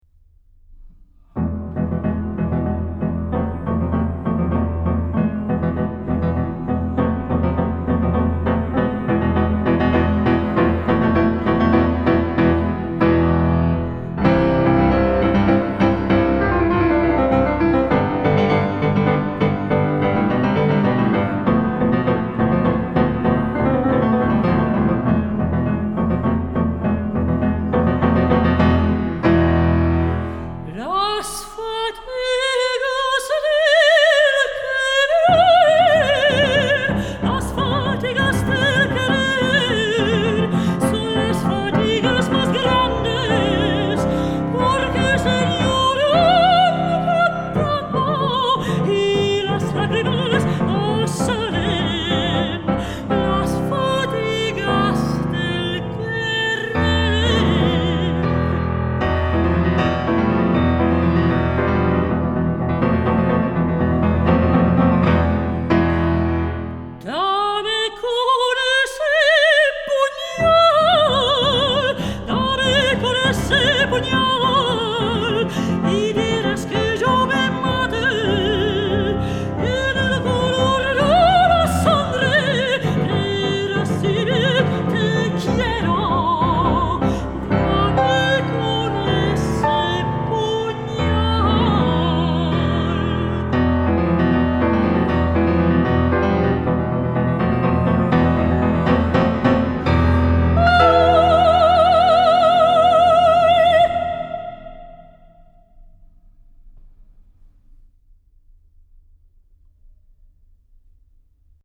Singing
Piano